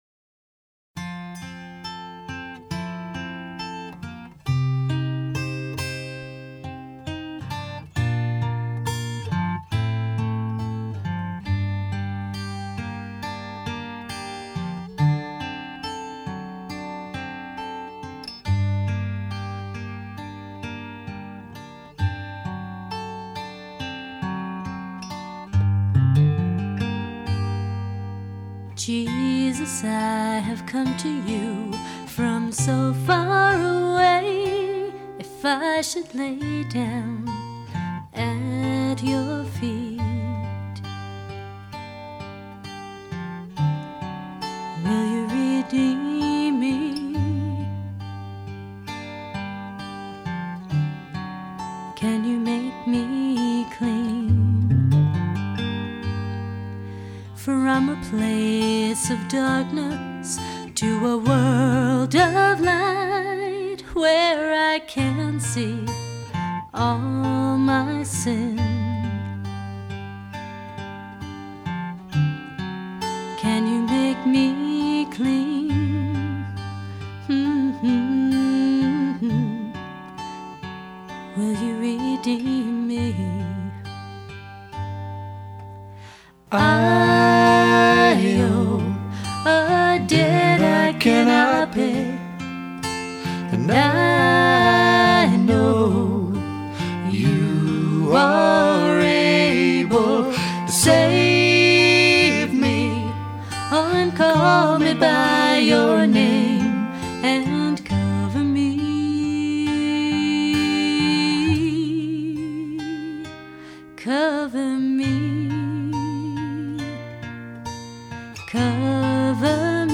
free christian music download
on lead guitar